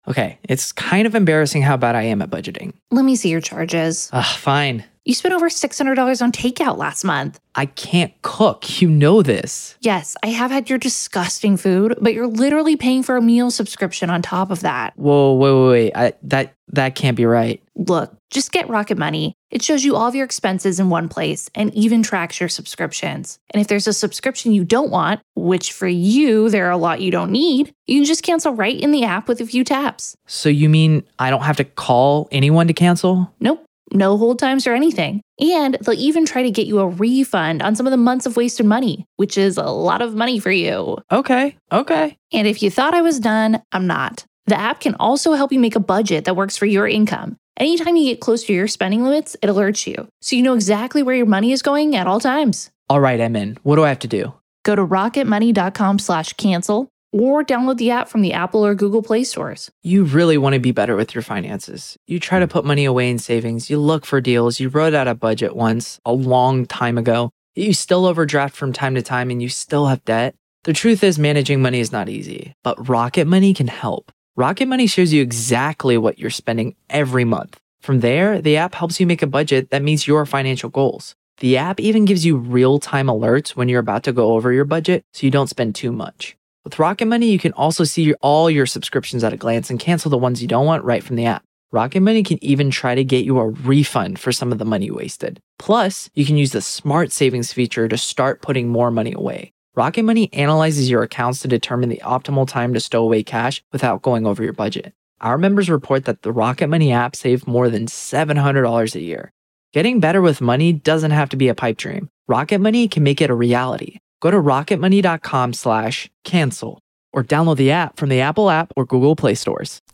The latest Spanish news headlines in English: May 20th